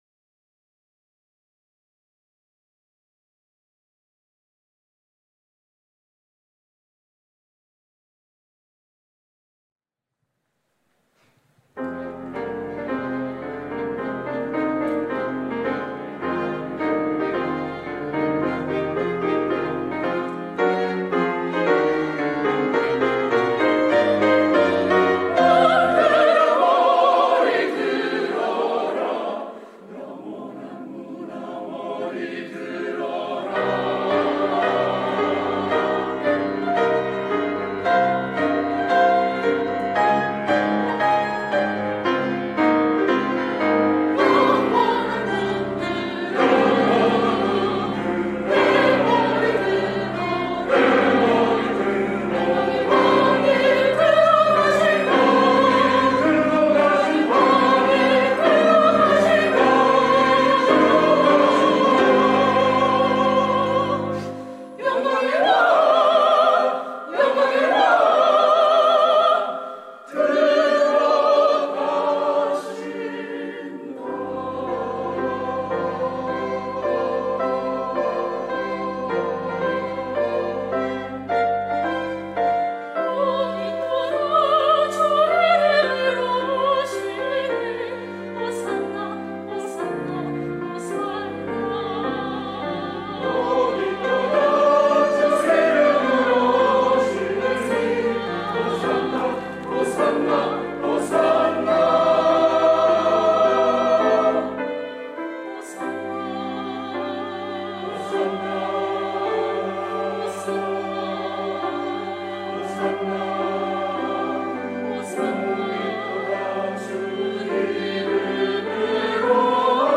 시온